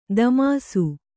♪ damāsu